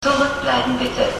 Departure Procedure and Sounds
About 16 short beeps announce the closing doors of the U-Bahn (the former vocal announcement "Zurückbleiben bitte!"